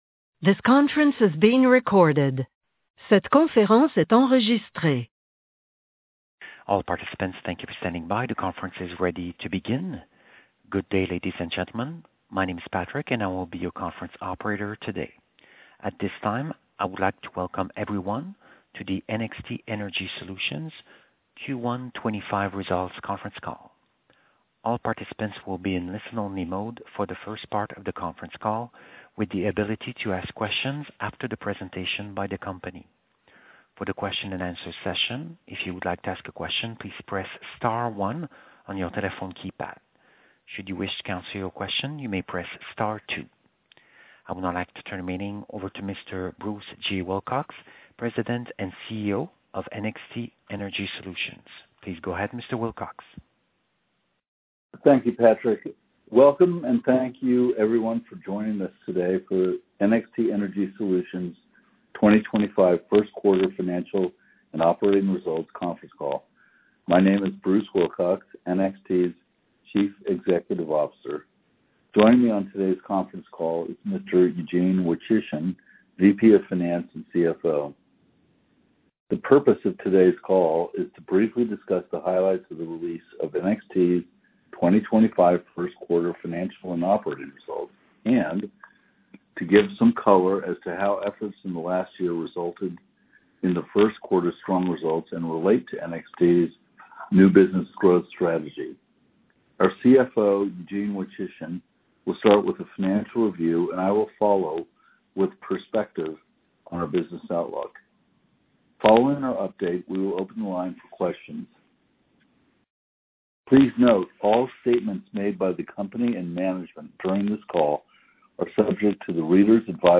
Investor Conference Calls - NXT Energy Solutions Inc.
Q1-25-Conference-Call-Recording.mp3